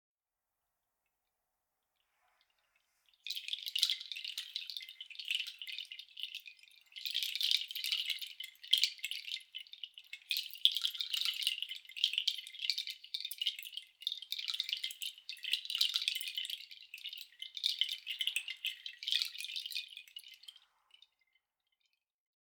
Meinl Sonic Energy Flower of Life Kenari Chimes Large - 10 Schnüre (SKFOLL)
Neben ihrem beruhigenden Klang sind sie auch ein toller Dekorationsartikel. Mit der Schlaufe an der Oberseite kann man die Chimes entweder bequem in der Hand halten oder aufhängen.